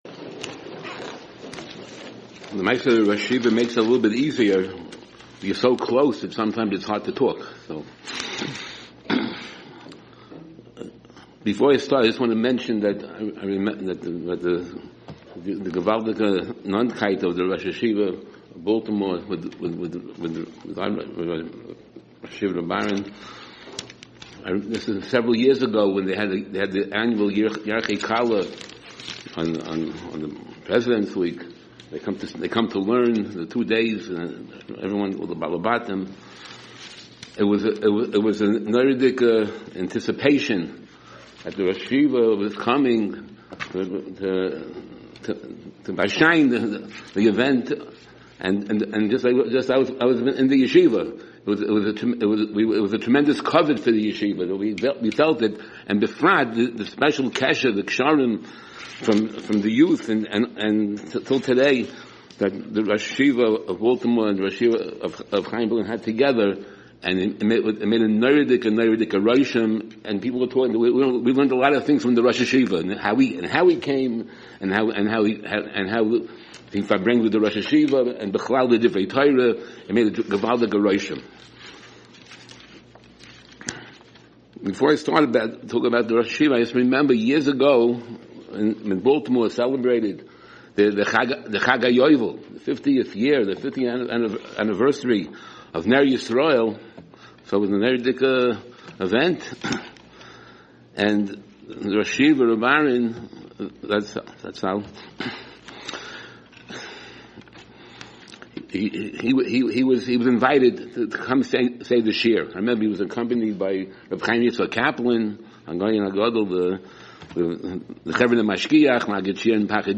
Hespaidim